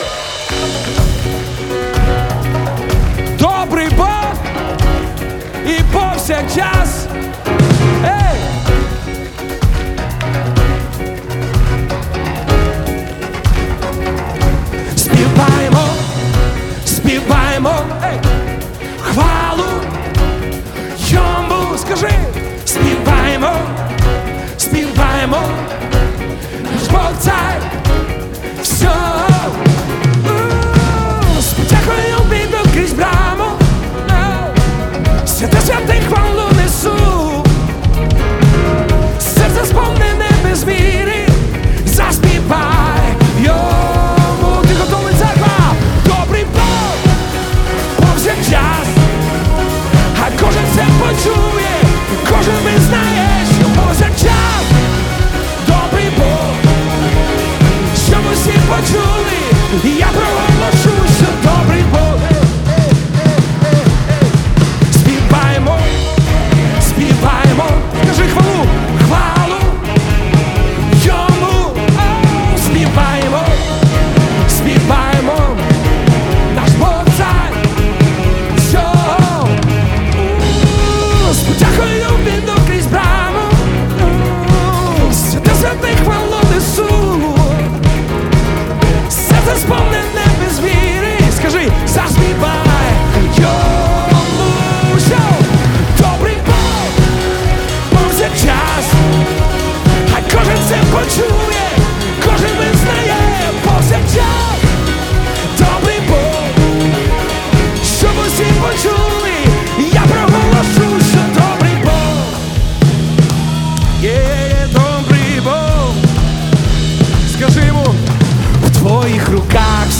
235 просмотров 899 прослушиваний 13 скачиваний BPM: 125